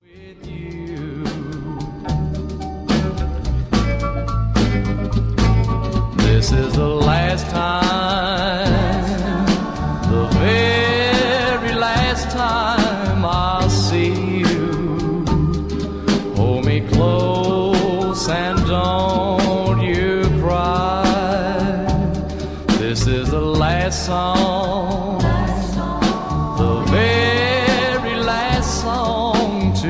a beautiful obscure early 1960\'s teen pop song